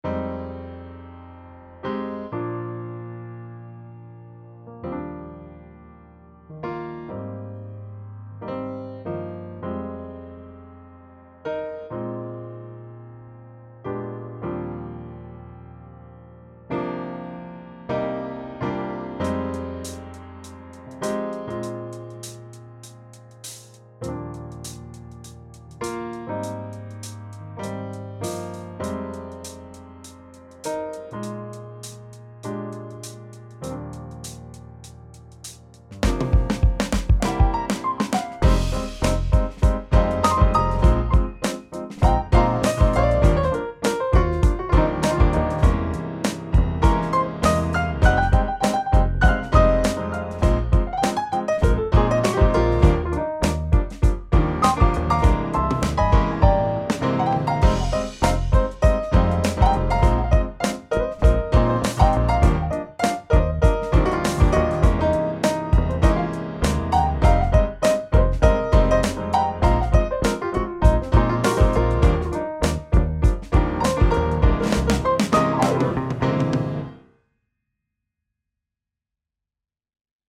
Used Studio Piano, and had Logic lay down a bass part and drums with session player. These sounds are incredible, I feel like I don't need to use any third-party plug-ins.